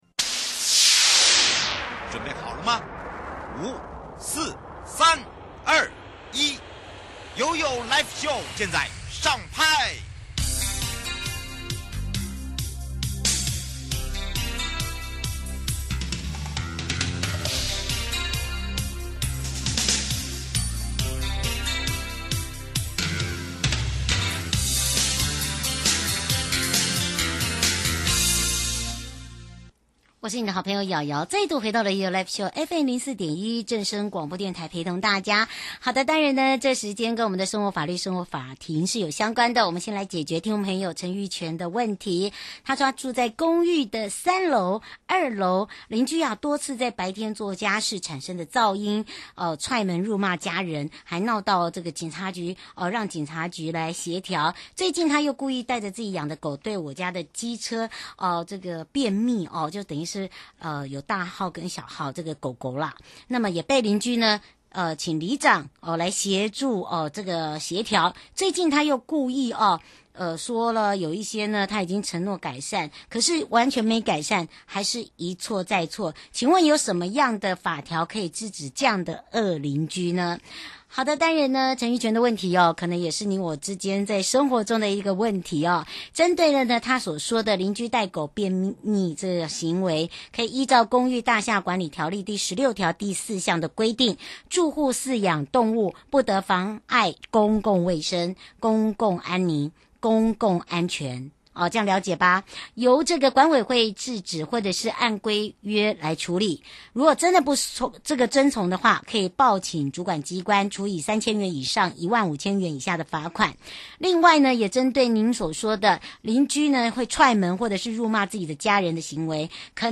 受訪者： 1. 台北地檢黃珮瑜主任檢察官 2. 法務部保護司黃玉垣保護司長 節目內容： 1. 犯罪被害人保護面面觀？因為他人的犯罪行為被害 而加害人不願意賠償 該怎麼辦？犯罪被害補償金的項目有哪些？金額有沒有上限？